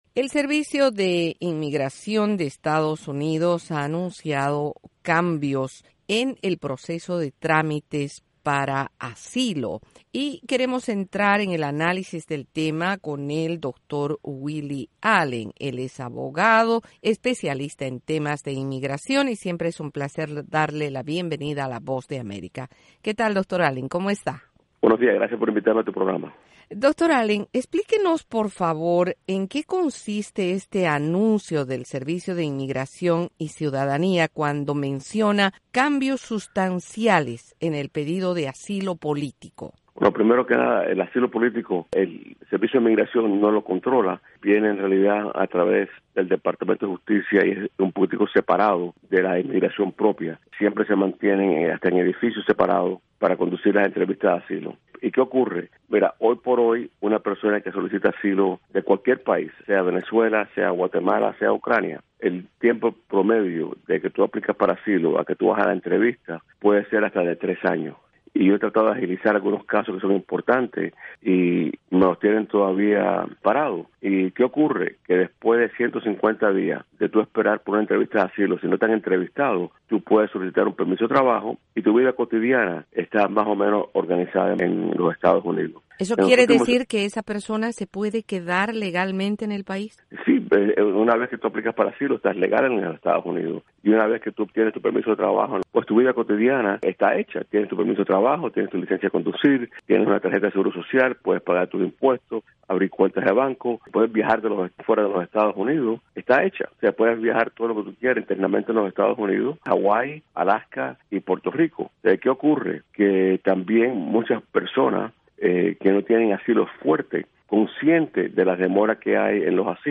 Para explicar los alcances de esta decisión, la Voz de América entrevistó al abogado experto en Inmigración